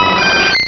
Cri de Leveinard dans Pokémon Rubis et Saphir.
Cri_0113_RS.ogg